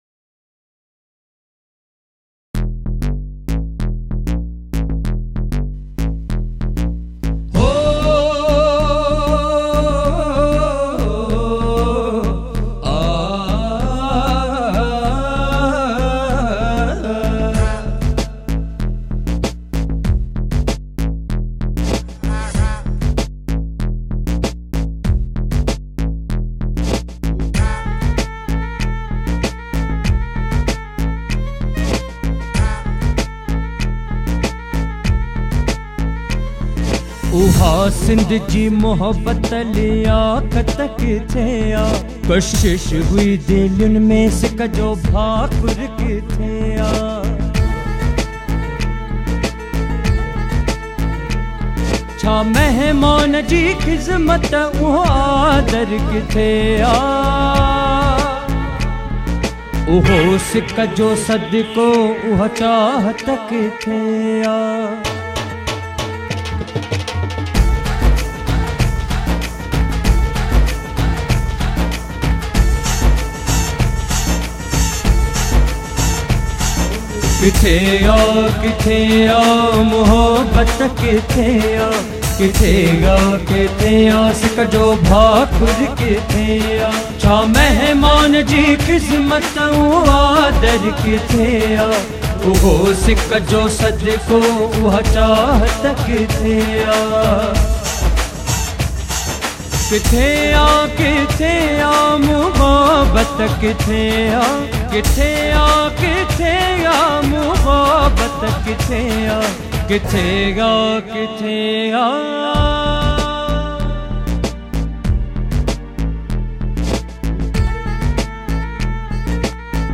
Sindhi Devotional songs